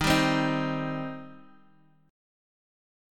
Eb6 Chord